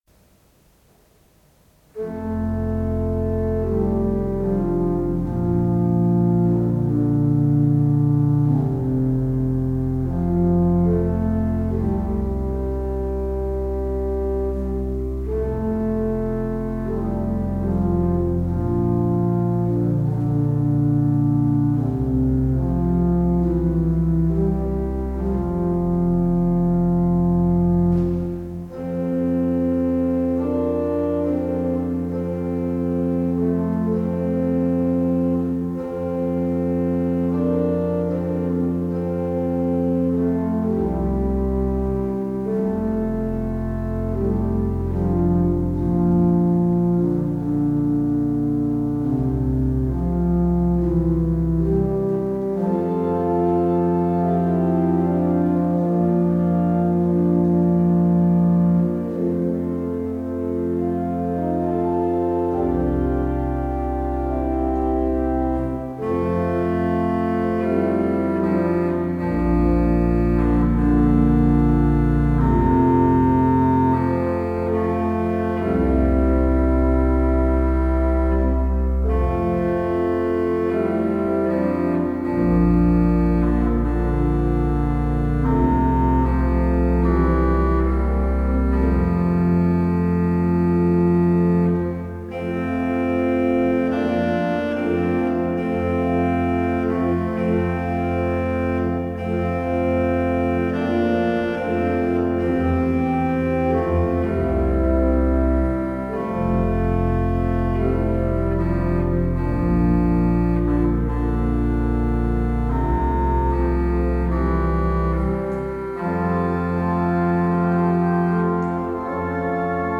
Worte & Musik zum Sonntag 17.